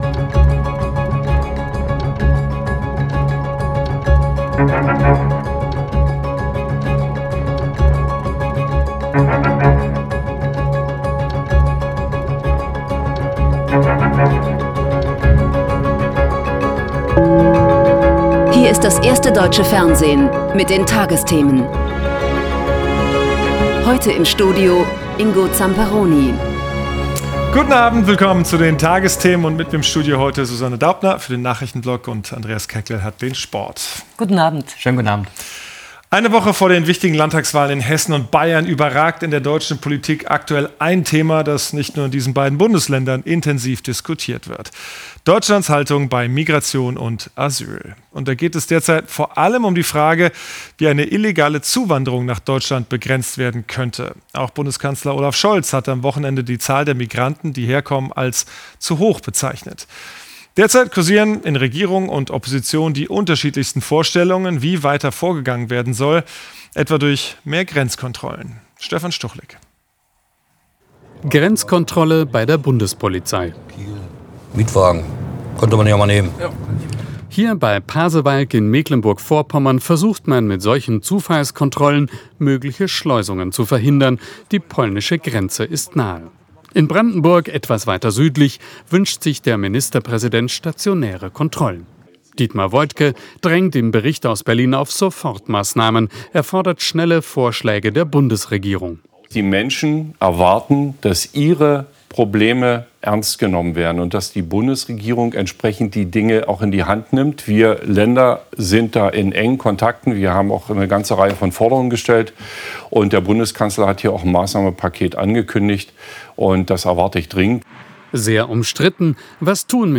… continue reading 2409 епізодів # Tägliche Nachrichten # Nachrichten # Tagesschau